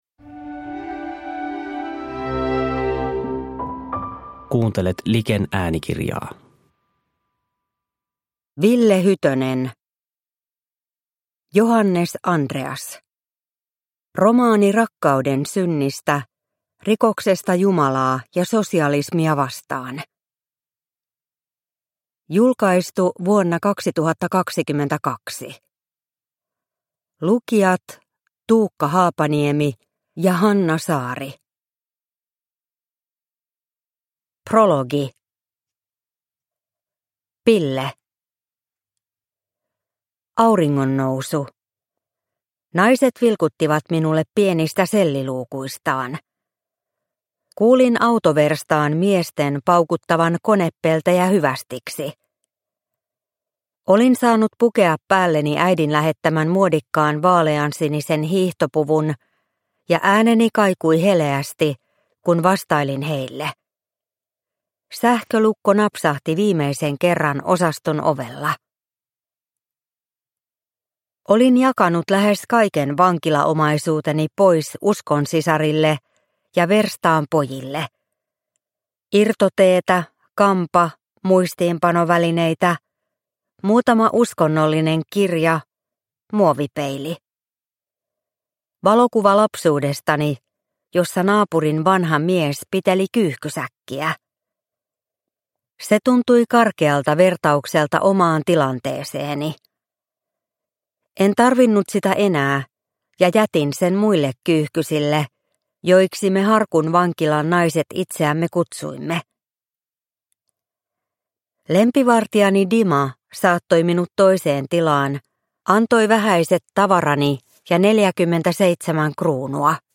Johannes-Andreas – Ljudbok – Laddas ner